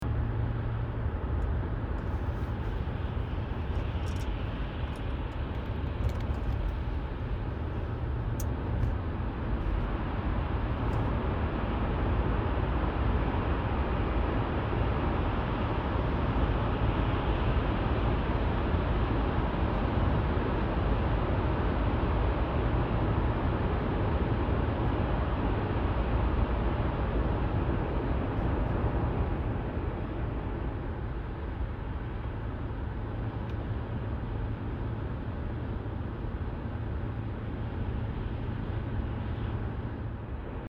17. Езда в тоннеле на автомобиле, проезд через туннель
ezda-v-tonnele.mp3